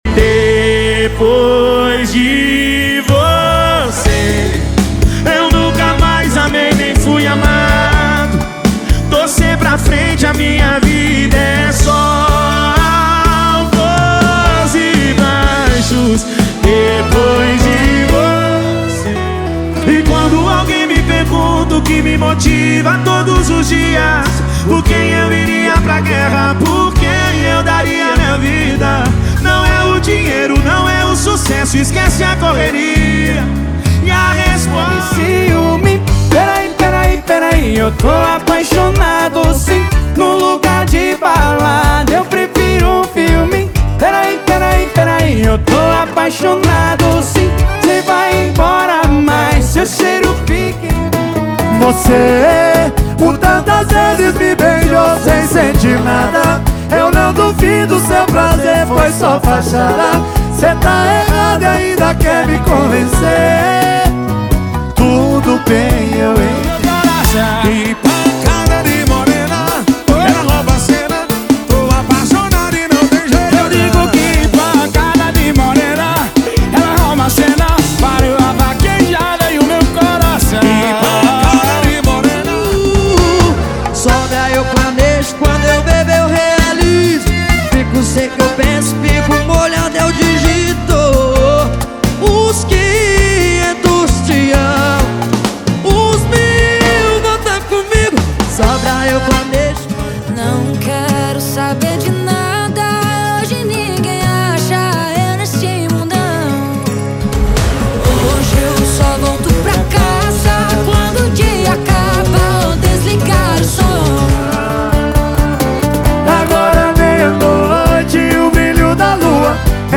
Os Melhores Sertanejo do momento estão aqui!!!
• Sem Vinhetas